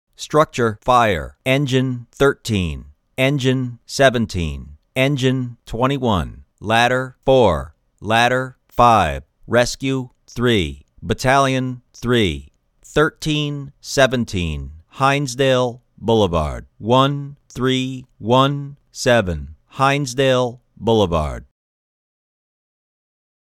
Automated Dispatch / CAD